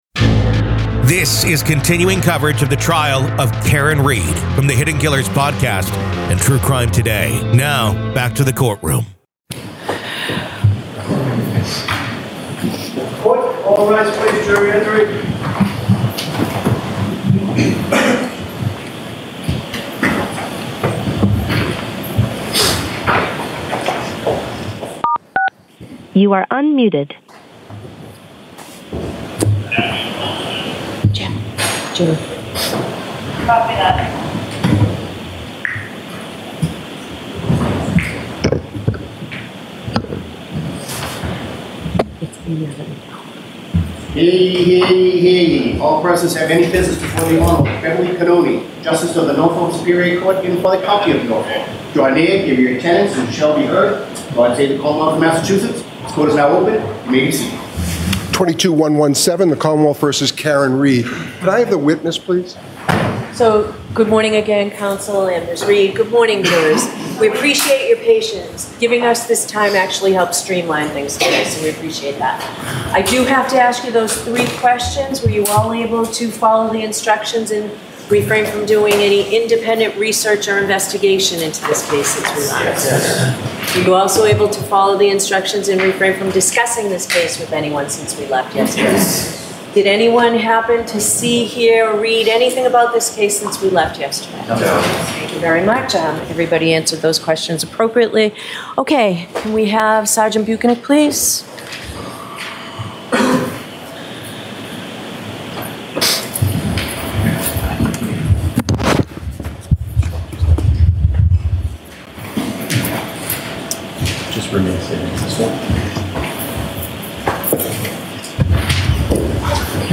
The Trial of Karen Read: Boyfriend Cop Murder Trial – MA v. Karen Read Day 21 Part 1
Welcome to a special episode of "The Trial of Karen Read," where today, we find ourselves inside the courtroom of the case against Karen Read.